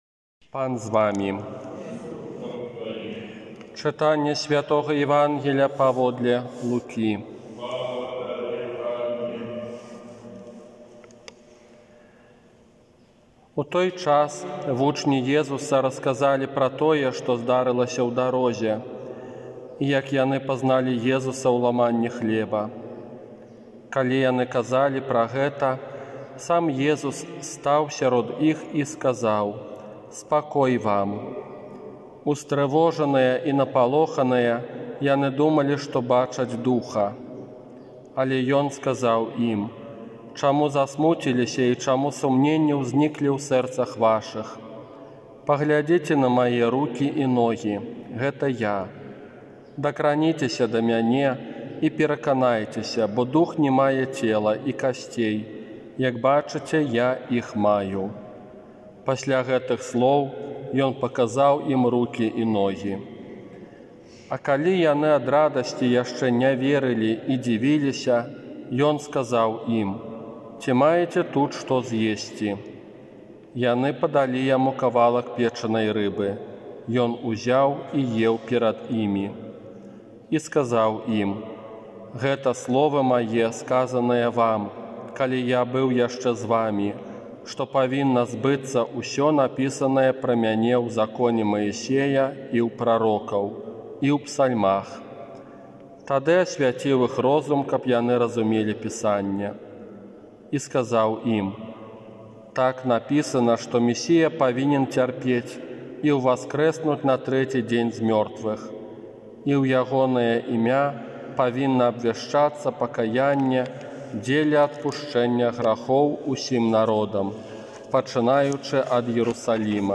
ОРША - ПАРАФІЯ СВЯТОГА ЯЗЭПА
Казанне на трэццюю Велікодную нядзелю